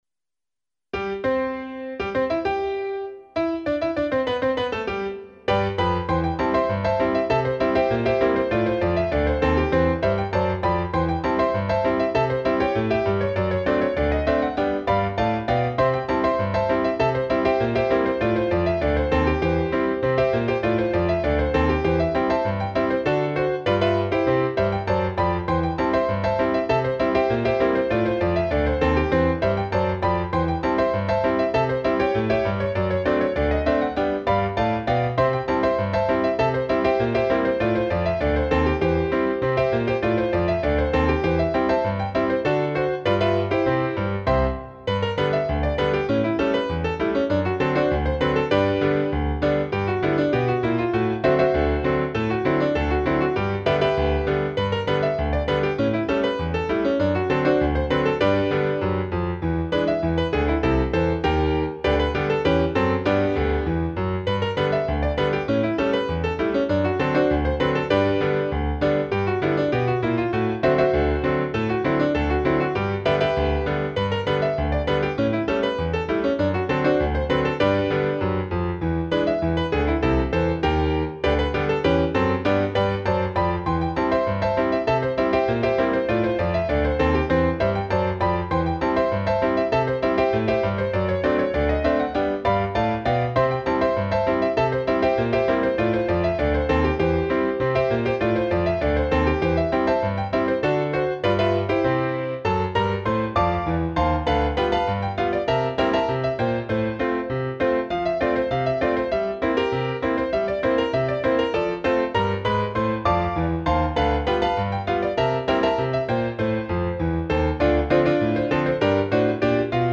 I made it with "Miroslav Philharmonik"
RAGTIME MUSIC